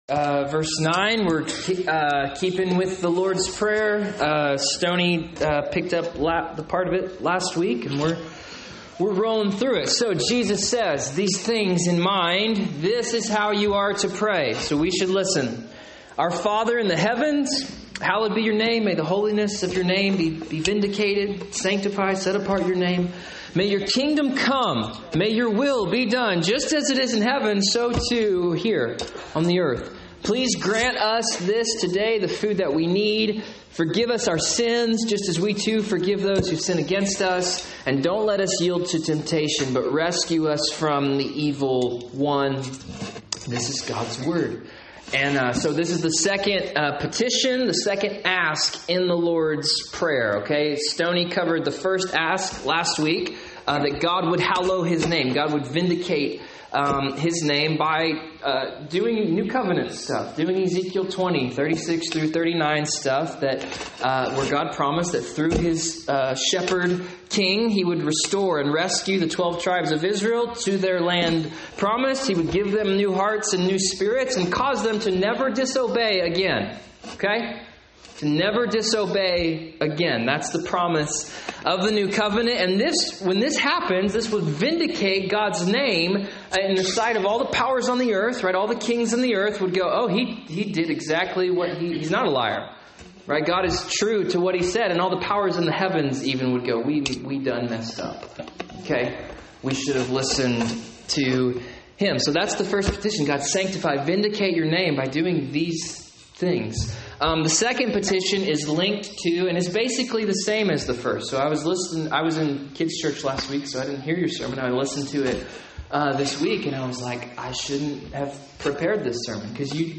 Sermons | Christian Life Church